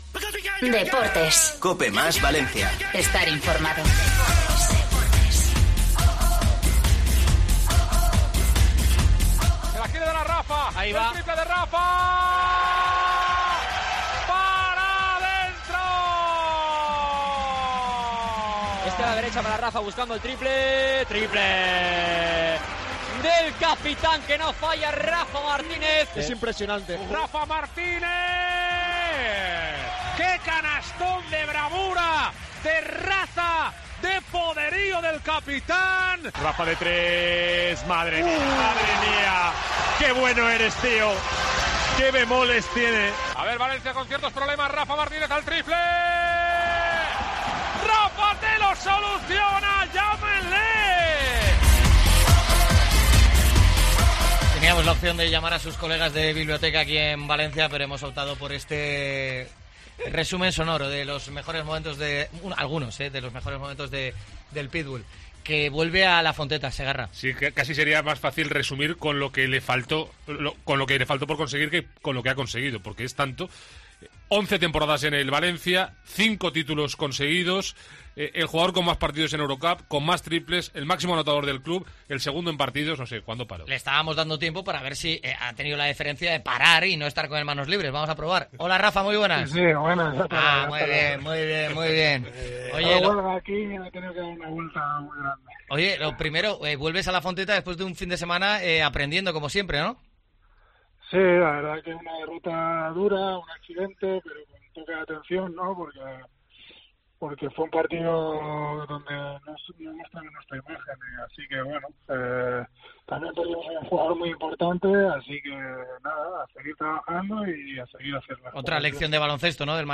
ENTREVISTA COPE
Rafa Martínez habla en COPE de su vuelta a Valencia